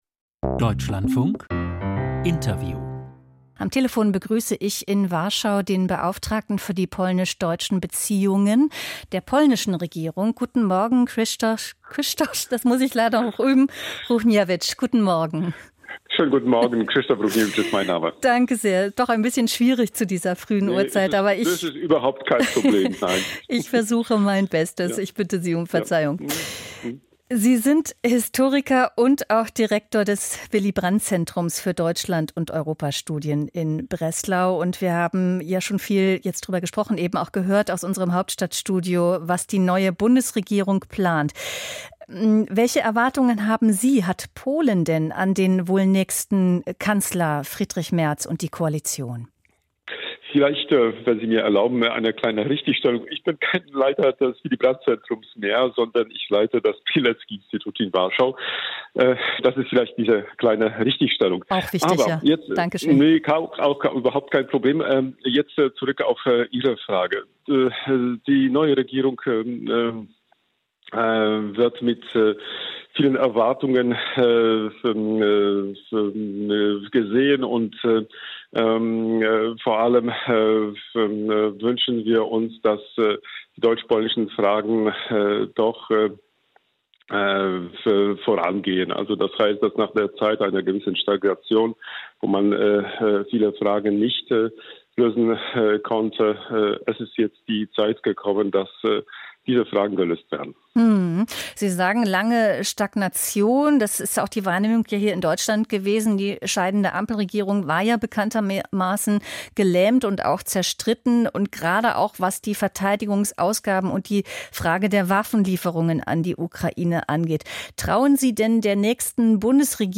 Interview Krzysztof Ruchniewicz, Deutschlandbeauftragter Polens